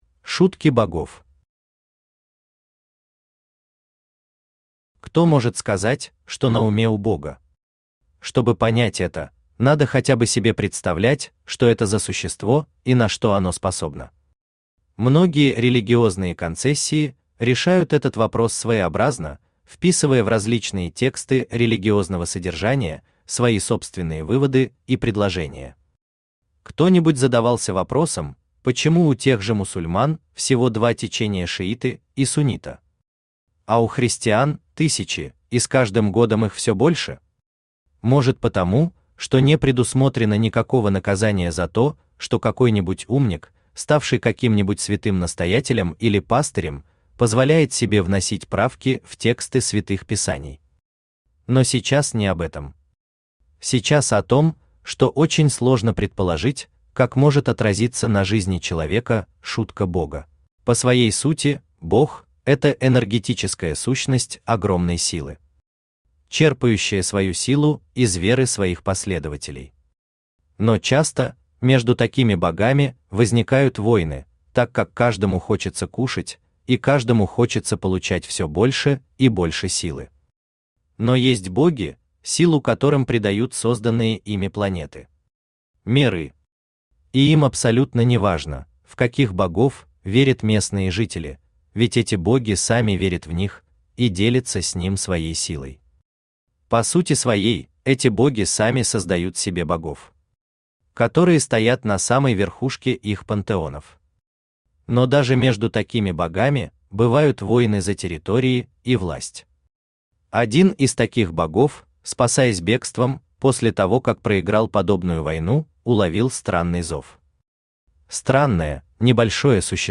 Великий лес Автор Хайдарали Усманов Читает аудиокнигу Авточтец ЛитРес.